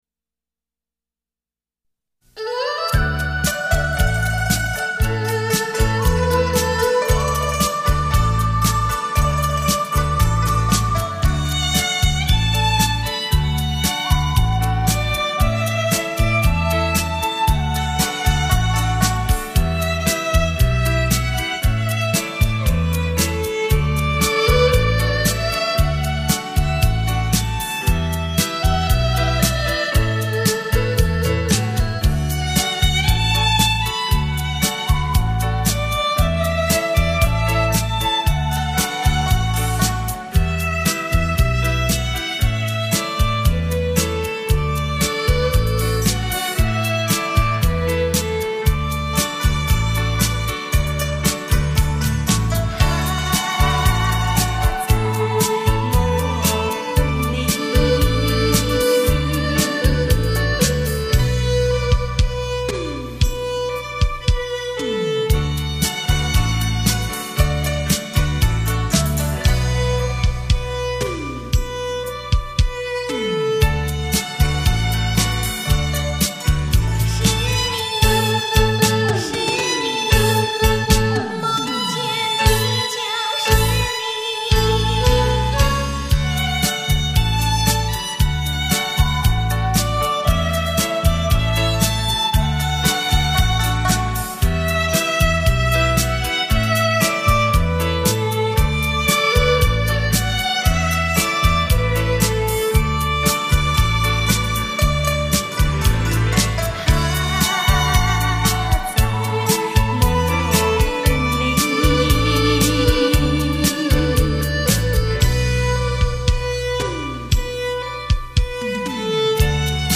（怀旧老曲 -- 民乐和西乐的结合）